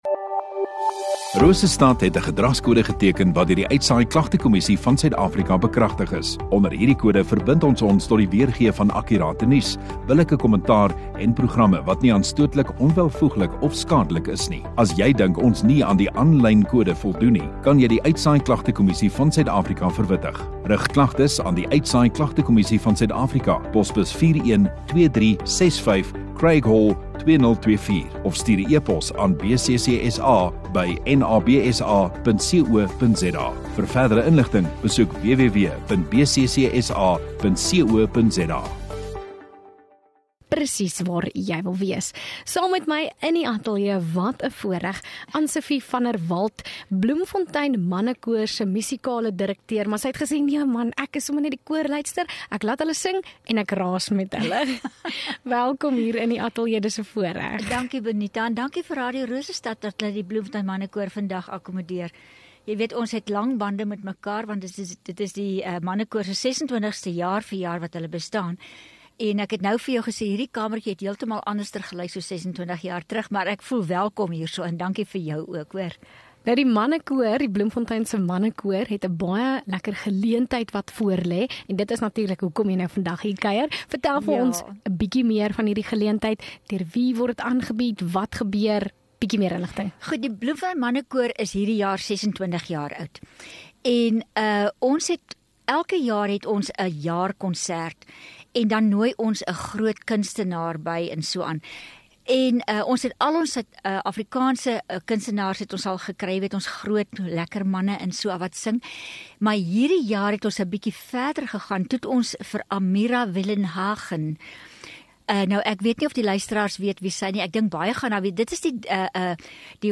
View Promo Continue Radio Rosestad Install Vermaak en Kunstenaars Onderhoude 15 Apr Mannekoor